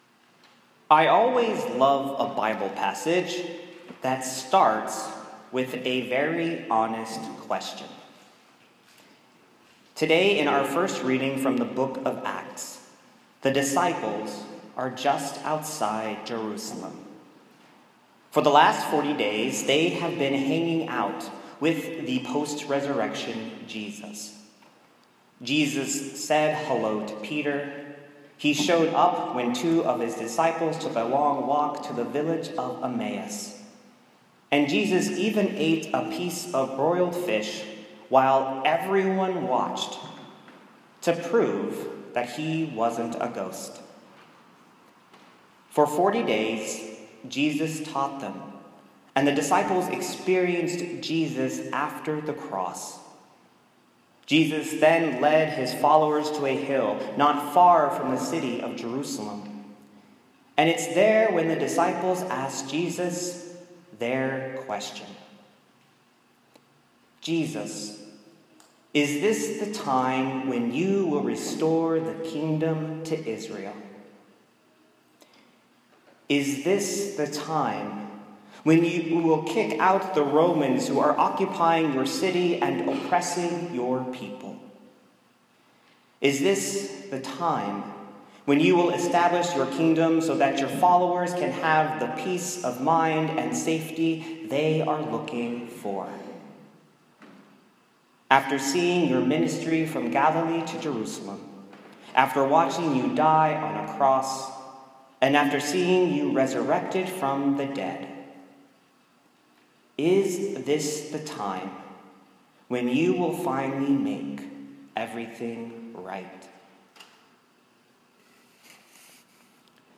Is This The Time: a sermon on a question that is really a prayer